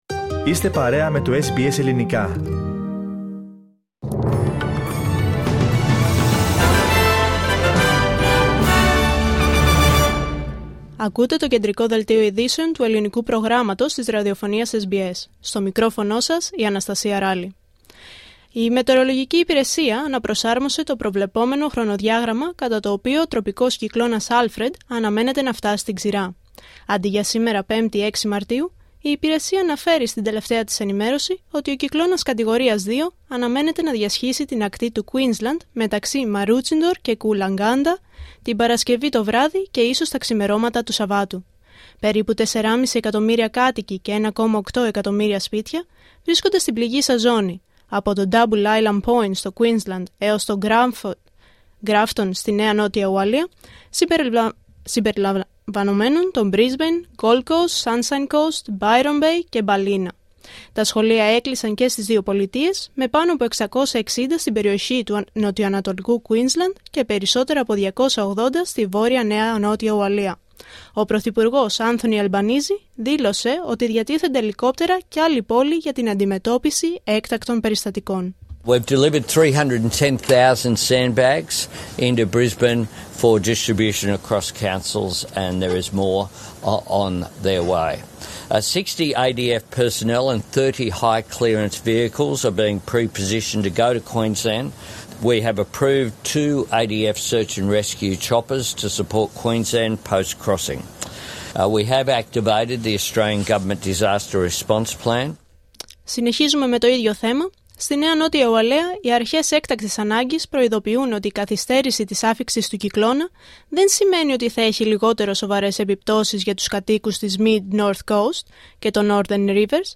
Δελτίο ειδήσεων Πέμπτη 6 Μαρτίου 2025